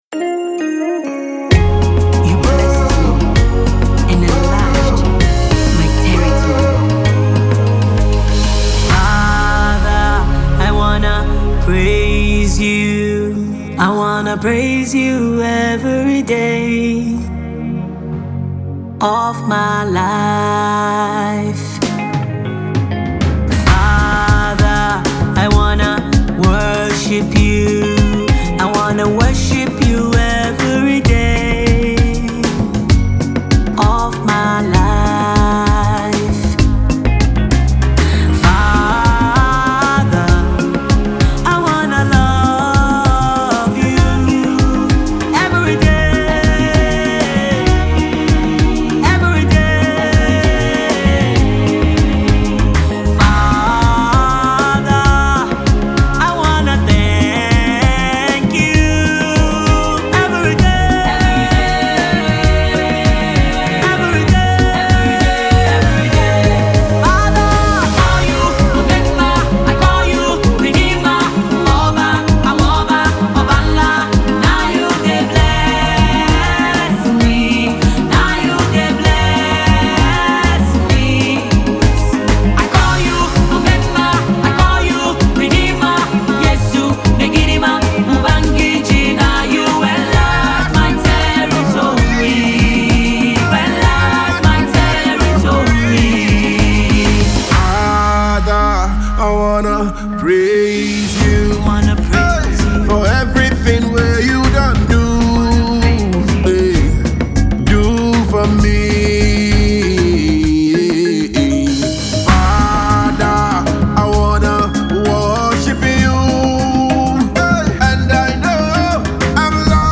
gospel music
vocal powerhouse